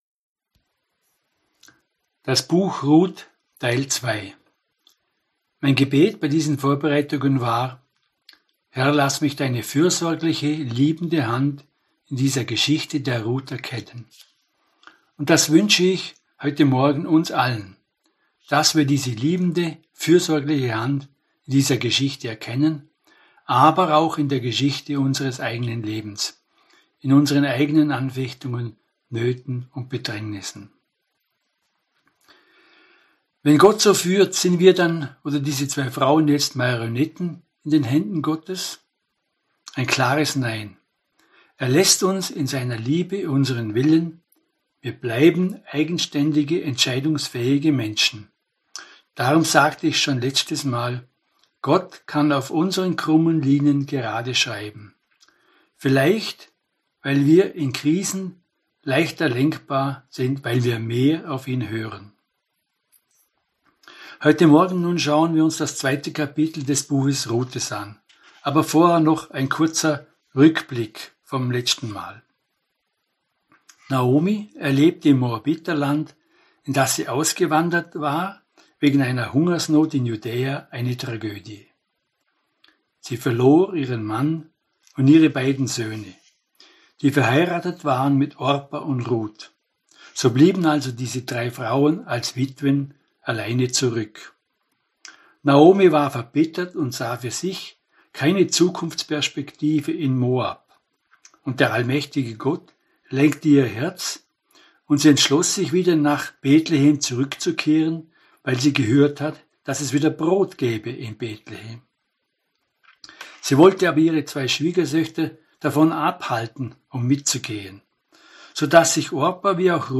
Teil 2 der Predigtreihe über Ruth (Kapitel 2) 1) die Arbeit der Ruth ( Verse 1 – 3 ) 2) die Barmherzigkeit des Boas ( Verse 4 – 18 ) 3) die Reaktion der Naomi ( Verse 19 – 23 ) Hinweis: Diese Predigt wurde aus technischen Gründen nach dem Gottesdienst durch Vorlesen erneut aufgezeichnet.